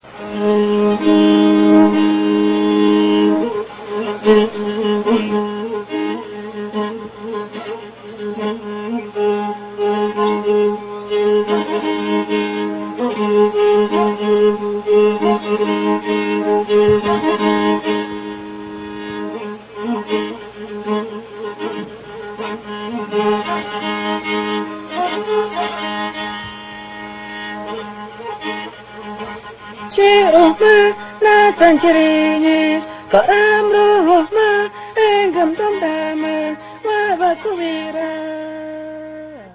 KITIAR INSTRUMENTO CORDOFONO FUNDEF
Violín rústico, tallado en una sola pieza de madera de cedro, caja acústica excavada y tapa armónica de cinc con líneas de agujeros que imitan las efes del violin europeo. Dos cuerdas de nylon se frotan con un rústico arco de cerdas de cola de caballo.
Ensamble: Voz y Violín
Característica: Toque ligado a la vida ceremonial y lo ejecutan fundamentalmente los ''uwischin'' (chamanes) para comunicarse con el mundo sobrenatural
Procedencia, año: Sucúa, Prov. Morona Santiago, Ecuador 1975